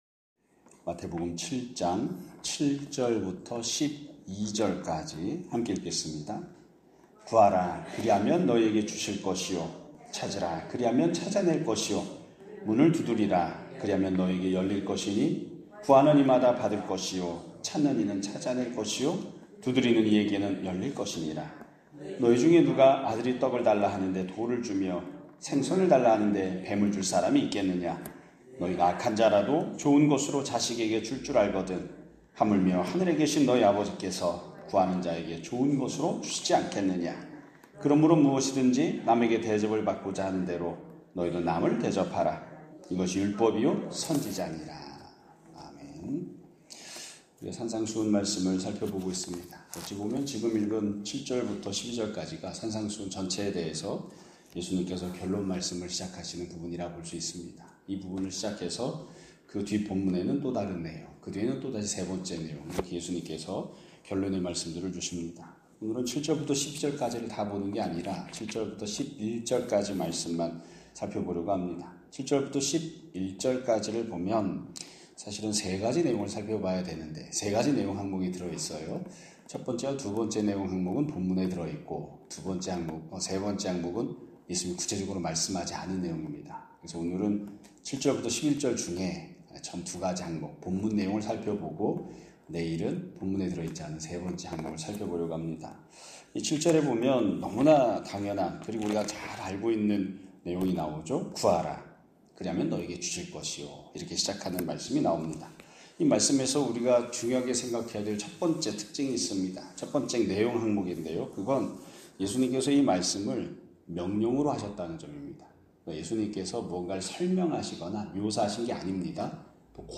2025년 6월 25일(수요일) <아침예배> 설교입니다.